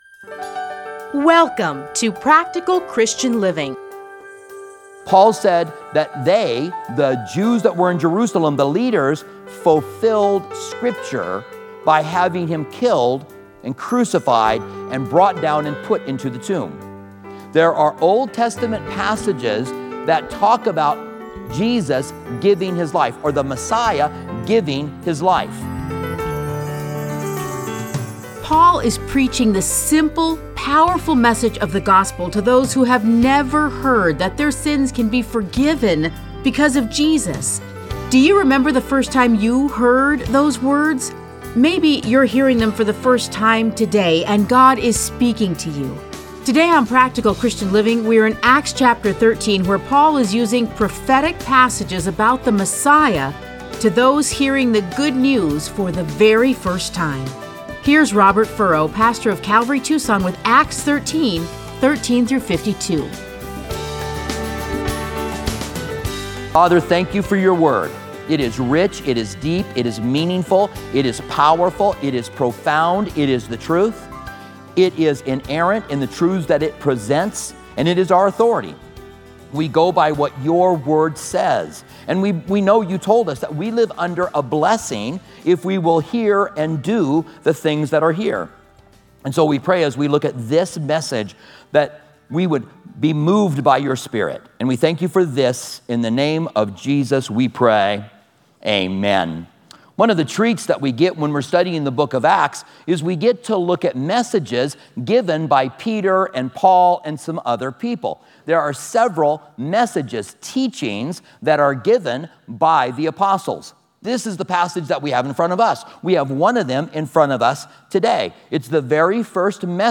Listen to a teaching from Acts 13:13-52.